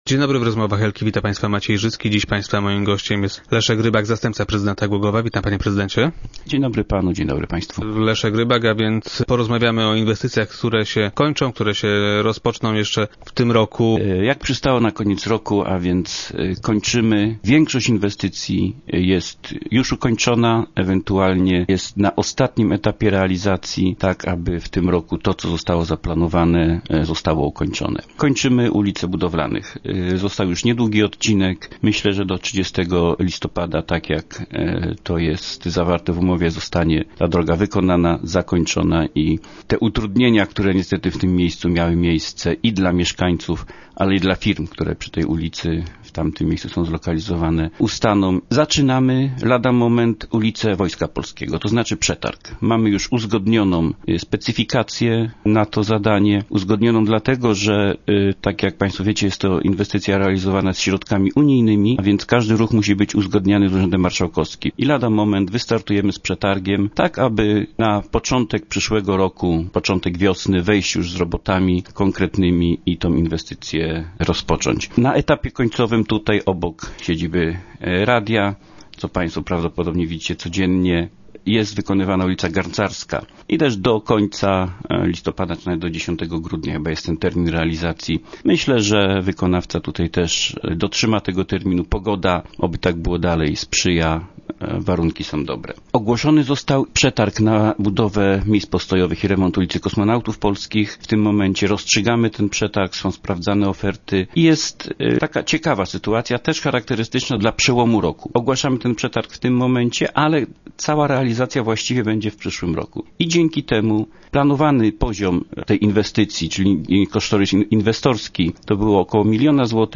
Jak zapewnia Leszek Rybak, zastępca prezydenta miasta. większość poddanych pod głosowanie zmian, będzie korzystna dla głogowian i przyjezdnych. wiceprezydent był dziś gościem Rozmów Elki.